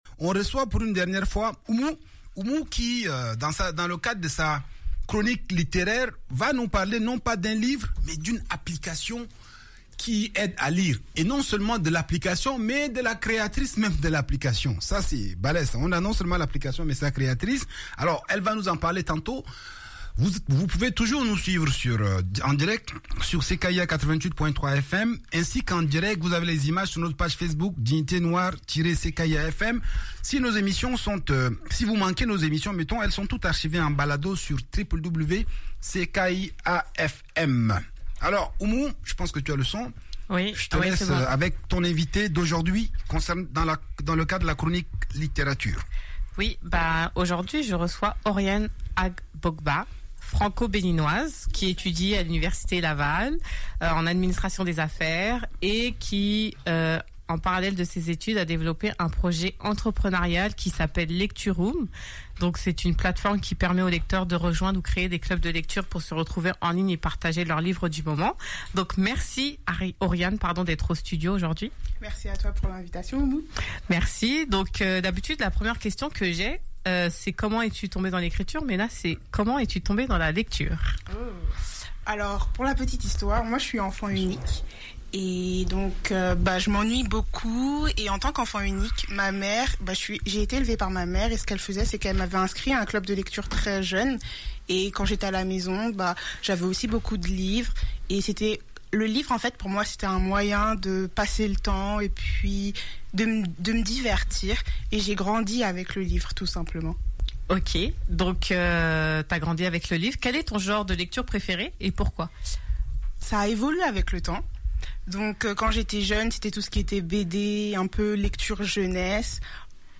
Voici mon entrevue avec elle: Découvrez Lecturoom. https